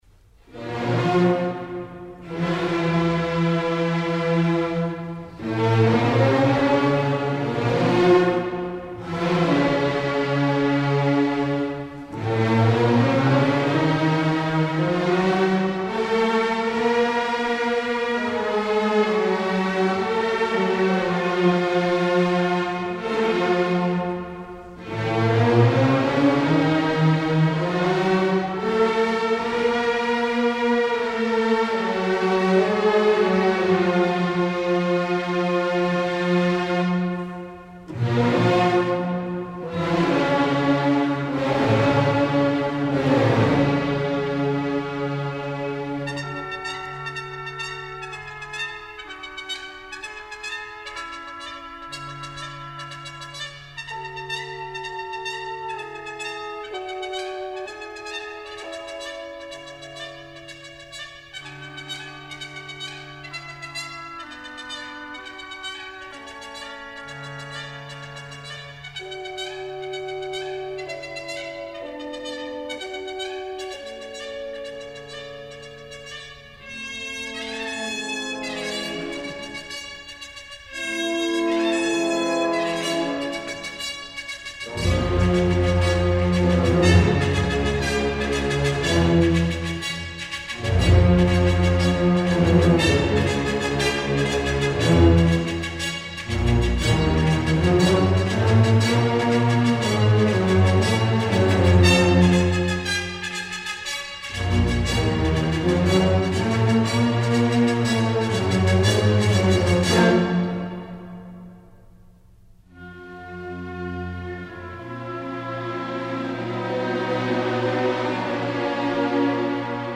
4. Breve melodia discendente "con dolore"
Goldenberg e Schmuyle (orchestrazione di Ravel)